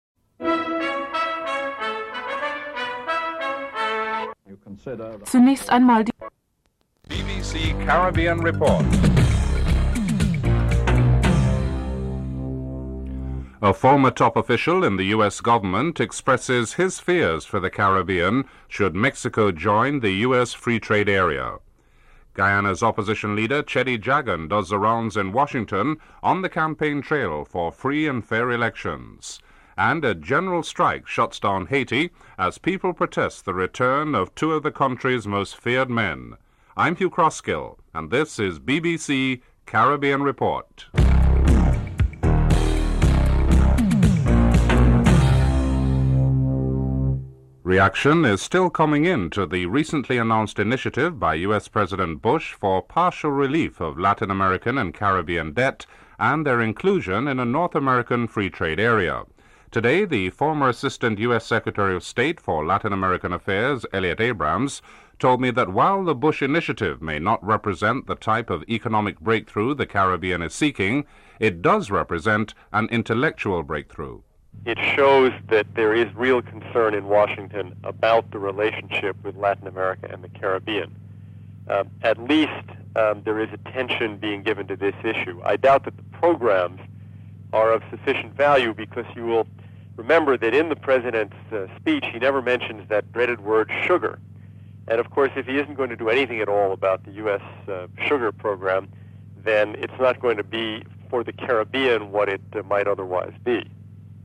Unrelated music and distorted audio at the beginning of the report.
Excerpt of speech from US president, George Bush on the Uruguay Round of trade negotiations (04:09-05:41)